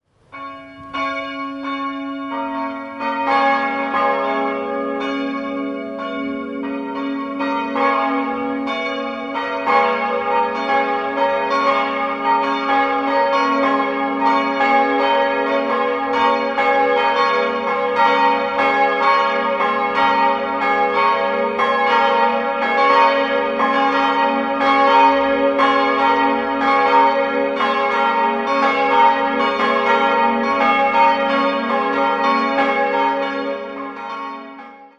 Eine größere Umgestaltung im Inneren erfolgte im Jahr 1996, als der Altarraum verändert und die neuen Glasfenster eingesetzt wurden. 3-stimmiges Gloria-Geläute: as'-b'-des'' Die große Glocke wurde 1999 angeschafft, ansonsten liegen keine genauen Informationen vor.